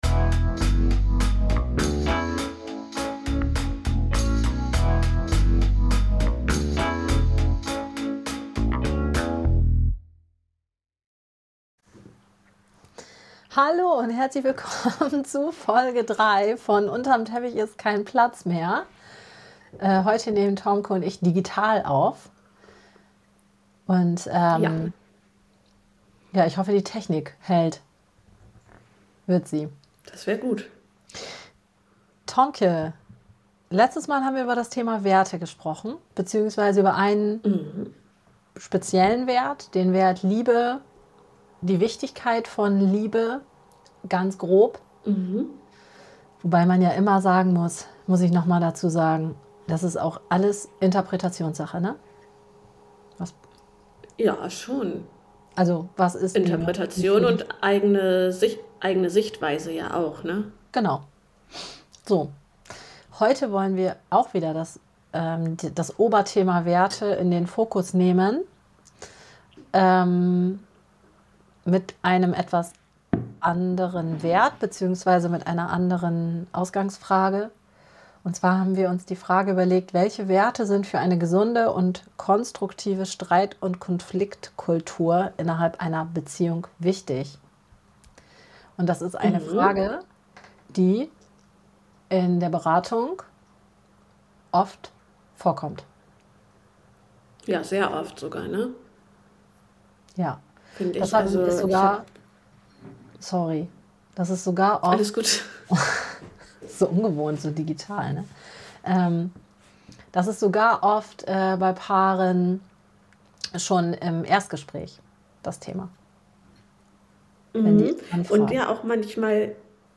In dieser Konversation wird erörtert, wie man in Auseinandersetzungen verständnisvoll bleibt und die eigene verletzliche Seite erkennt. Es wird betont, dass es nicht immer möglich ist, zu einer Einigung zu kommen, und dass innere Konflikte oft das Verständnis behindern.